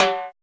626 TIMB HI.wav